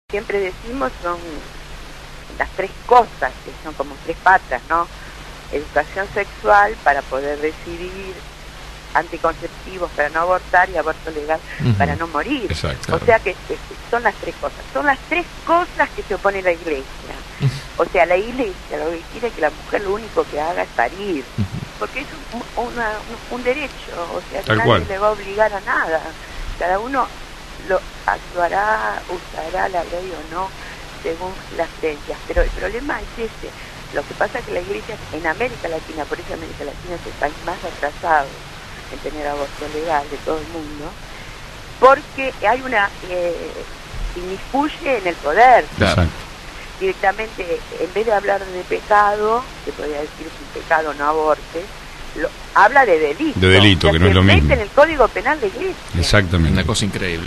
médica y militante feminista